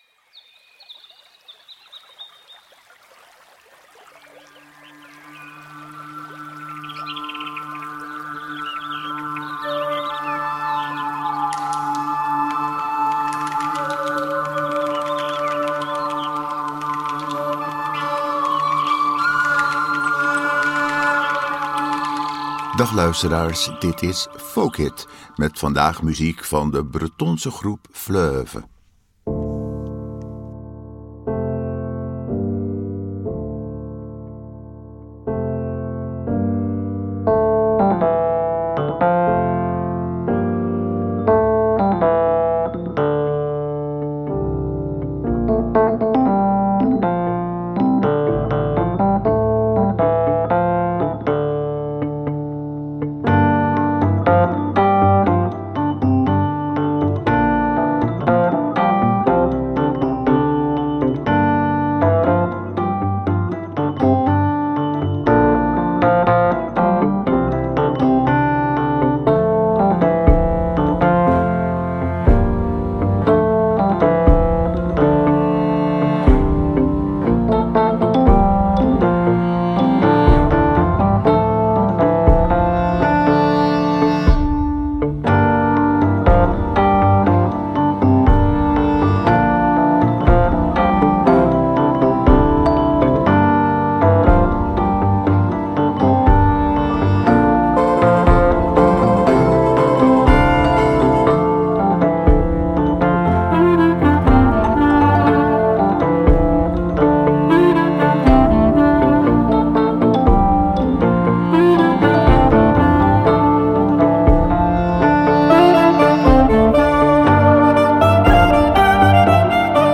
klarinet
toetsinstrumenten